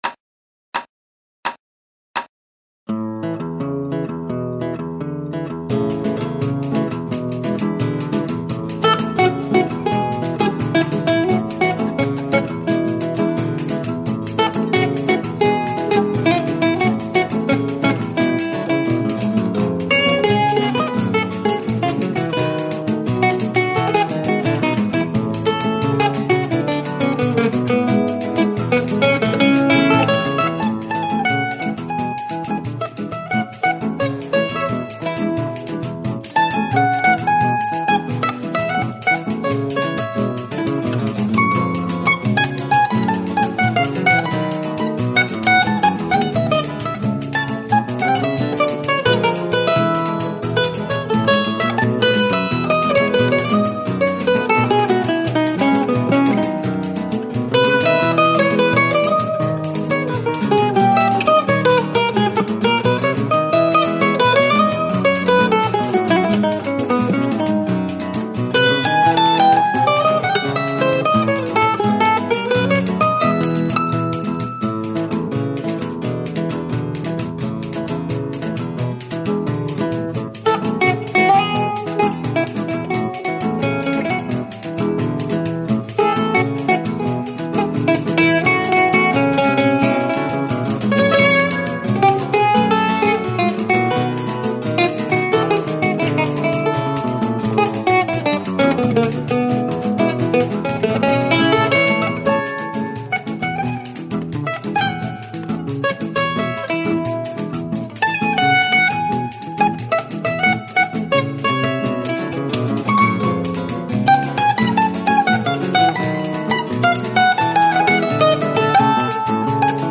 pandeiro
mandolin
7-string guitar
6-string guitar
A major
Complete track without cavaquinho